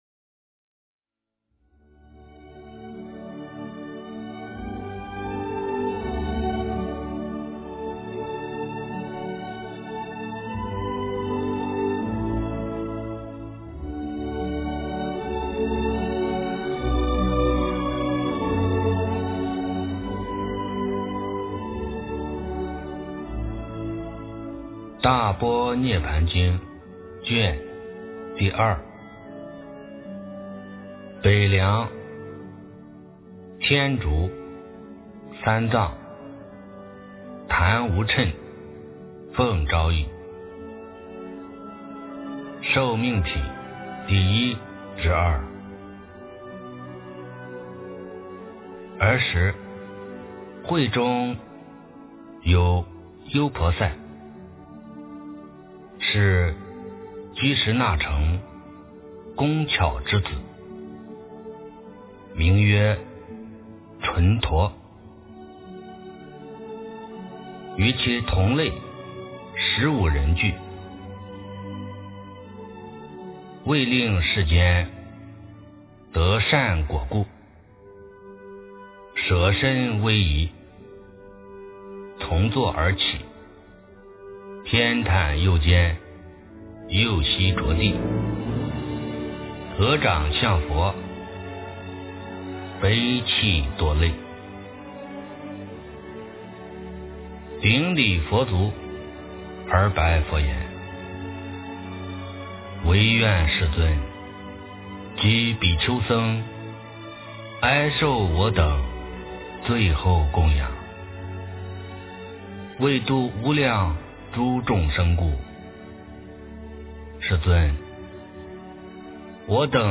大般涅槃经02 - 诵经 - 云佛论坛